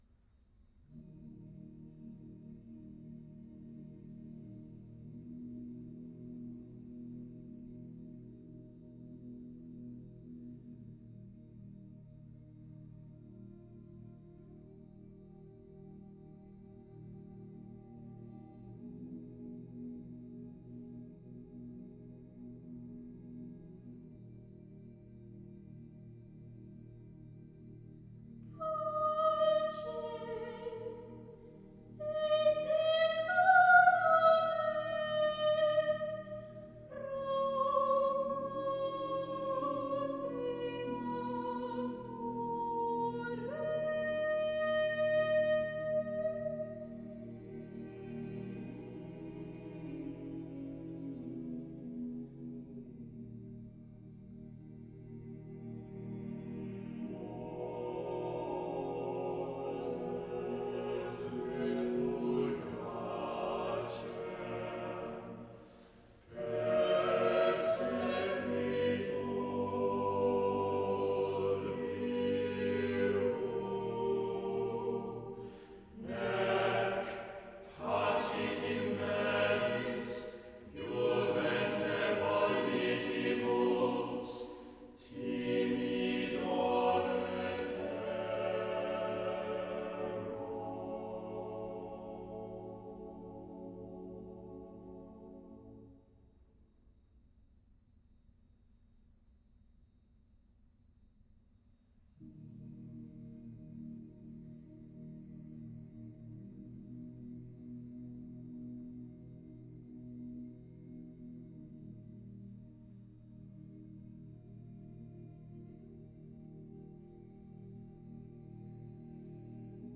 1. The opening chords, humming almost at whisper-level.
Tags2010s 2013 Britain Choral holidays modern